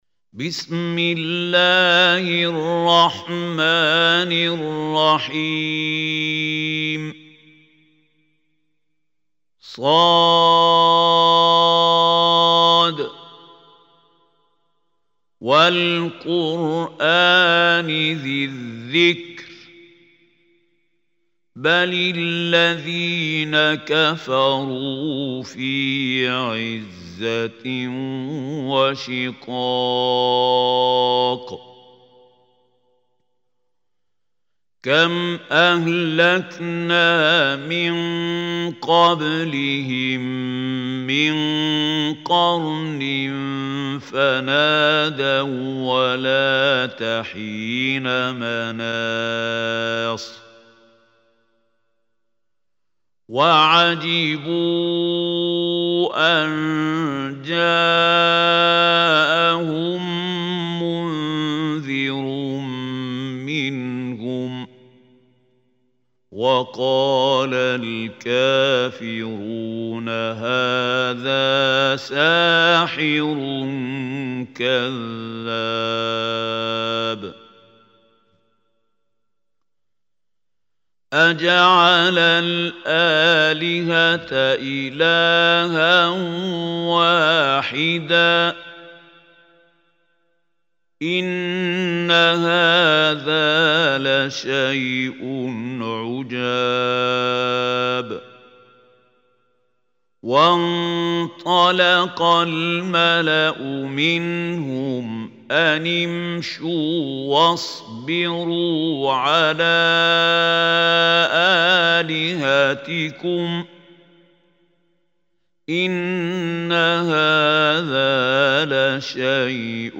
Surah Saad Recitation by Mahmoud Khalil Hussary
Surah Saad is 38 surah of Holy Quran. Listen or play online mp3 tilawat / recitation in arabic in the voice of Sheikh Mahmoud Khalil Al Hussary.